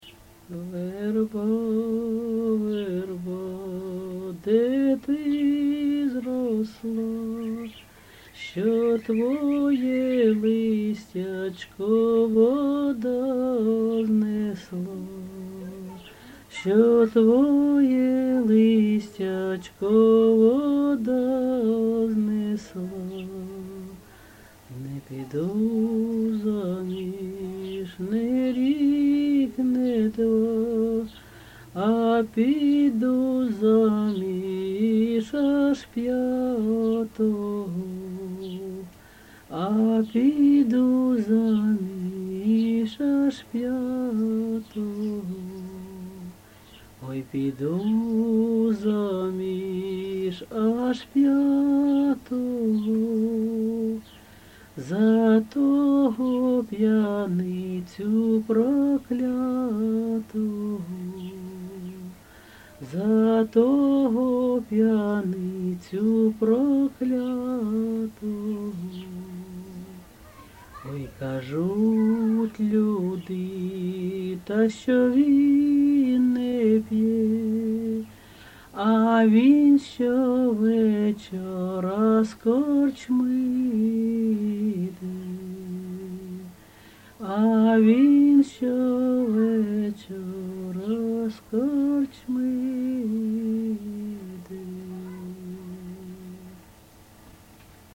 ЖанрПісні з особистого та родинного життя
Місце записус. Серебрянка, Артемівський (Бахмутський) район, Донецька обл., Україна, Слобожанщина